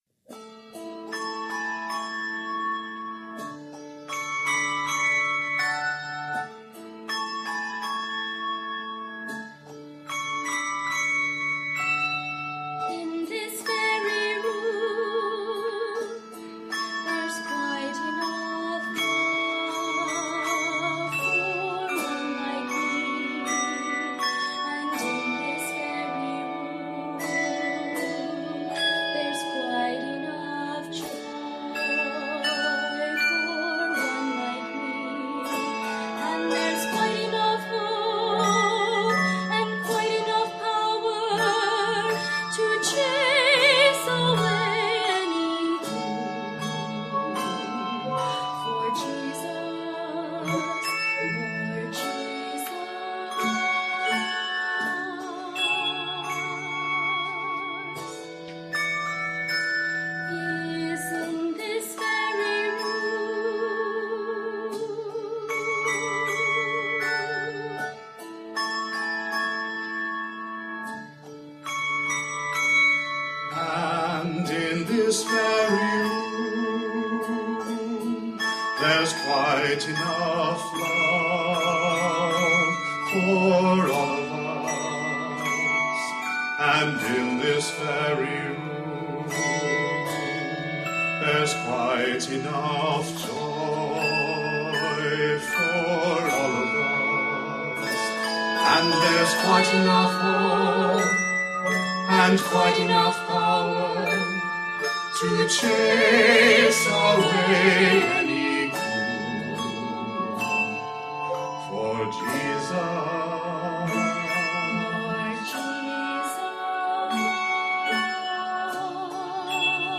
for solo or duet voices and handbell accompaniment